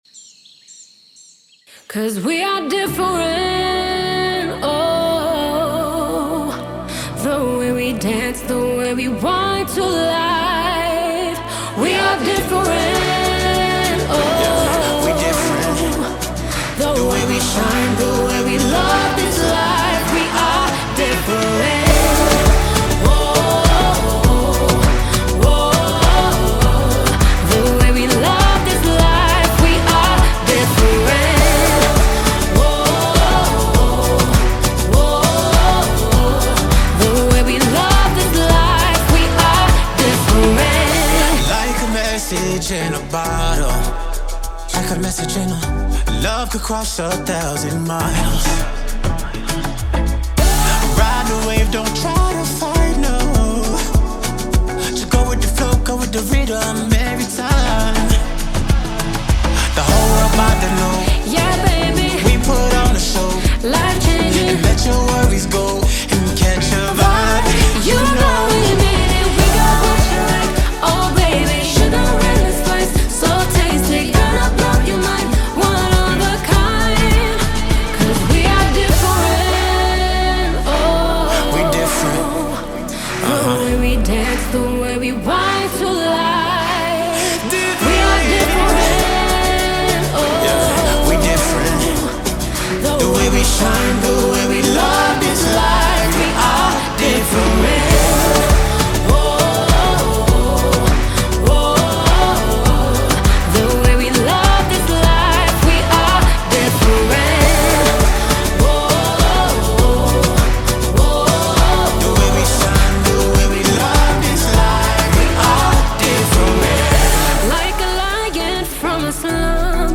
AudioR&B
global pop-Afro fusion single
recorded in 2025 at 2101 Studios in Dubai